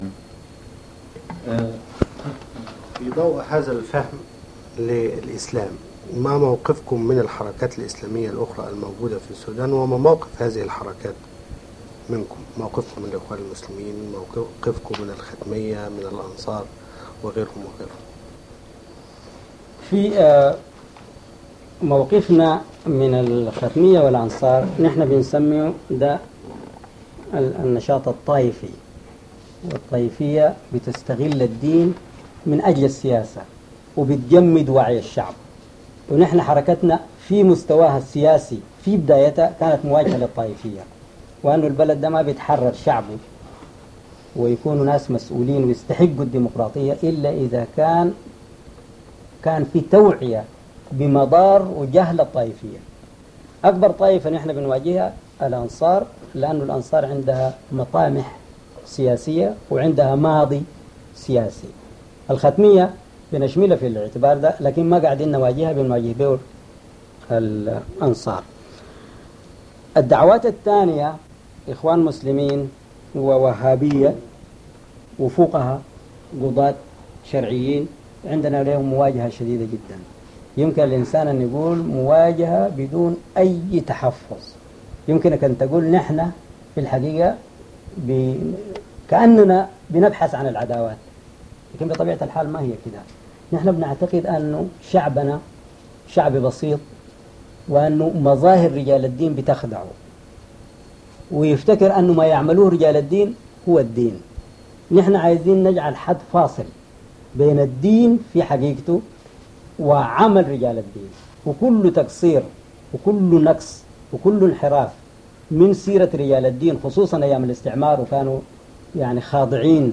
لقاء الأستاذ محمود محمد طه ببعض الإعلاميين المصريين
لقاء الأستاذ محمود محمد طه ببعض الإعلاميين المصريين المهدية - 29 يناير 1979 headset المشغل الصوتي الجزء الأول الجزء الثاني الجزء الثالث الجزء الرابع الجزء الخامس الجزء السادس الجزء السابع الجزء الثامن الجزء التاسع